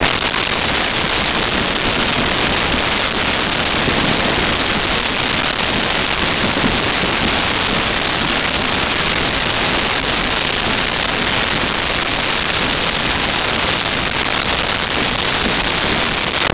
Train Boxcar Clacks 01
Train Boxcar Clacks 01.wav